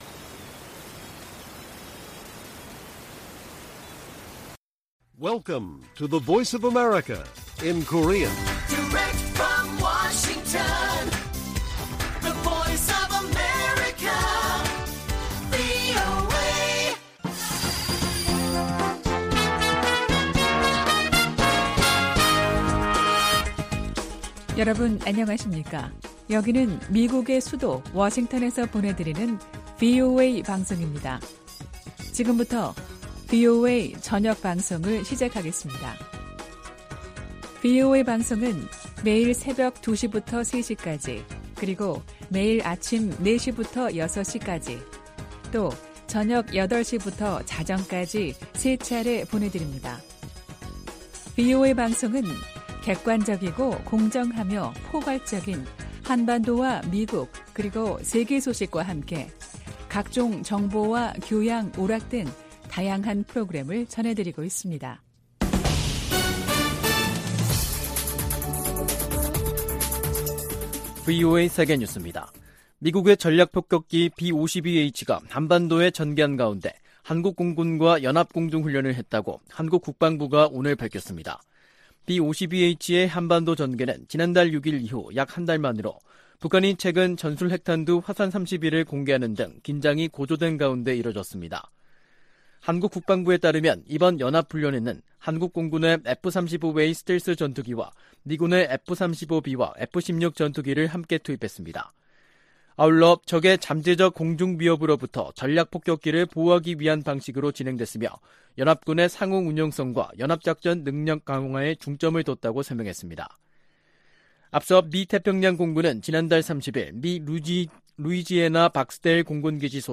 VOA 한국어 간판 뉴스 프로그램 '뉴스 투데이', 2023년 4월 5일 1부 방송입니다. 유엔 인권이사회가 북한의 조직적인 인권 침해를 규탄하고 개선을 촉구하는 결의안을 채택했습니다. 미국 국무장관이 현재 당면한 도전은 세계적인 것이라며 나토와 아시아 국가들의 협력 확대 중요성을 강조했습니다. 미국 공군이 이달 중순 시험 발사할 대륙간탄도미사일(ICBM) 미니트맨 3는 북한 정권을 끝낼 수 있는 위력을 가진 무기라고 미 군사 전문가들이 평가했습니다.